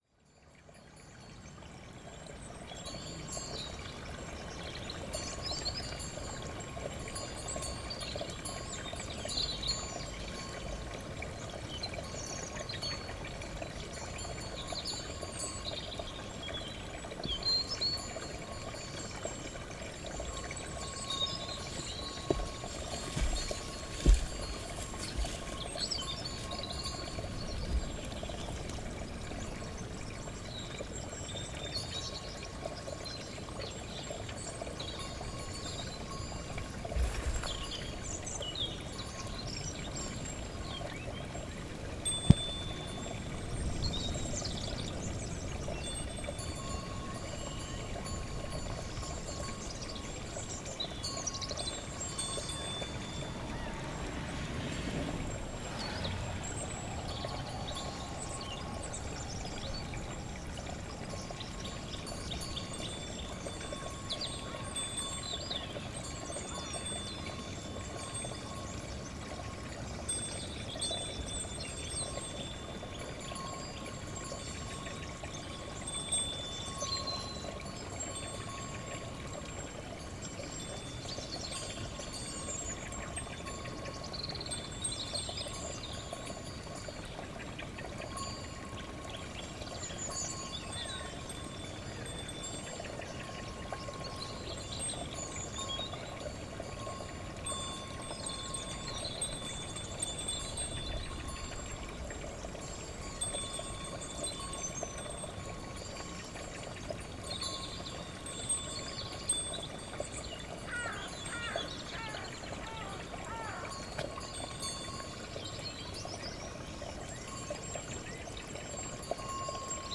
NODAR.00522 – Lordosa: Ovelhas a pastar em Pousa Maria (I)
O som dos chocalhos das ovelhas numa manhã de Inverno, um campo de pastoreio, um rego de água, uma avioneta, o sino da Igreja, um galo na distância. Paisagem sonora de ovelhas a pastar em Pousa Maria, Lordosa a 18 Fevereiro 2016.